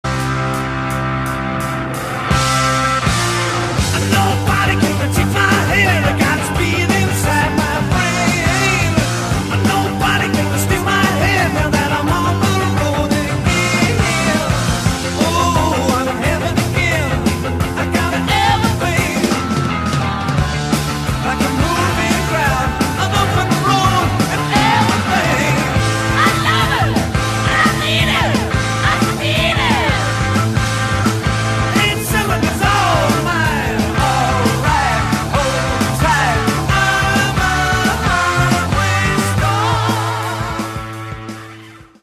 Kategorien POP